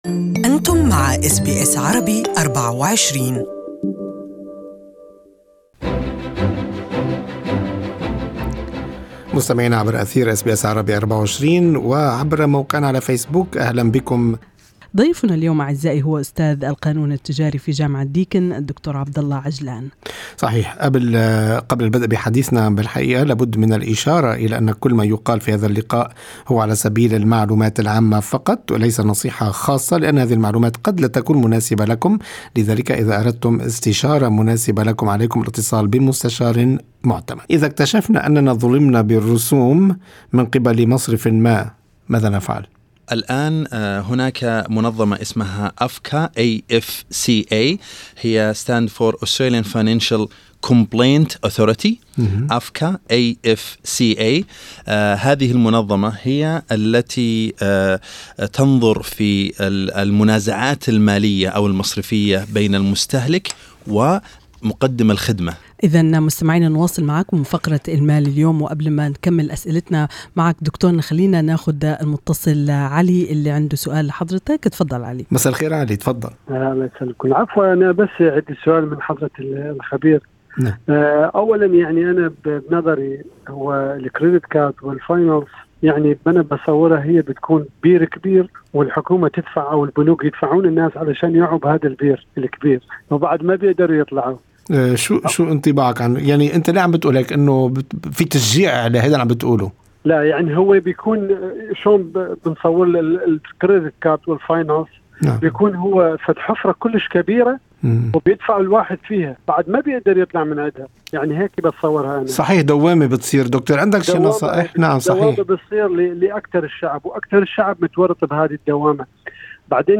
للمزيد من النصائح يمكن الاستماع للمقابلة في الملف الصوتي المرفق.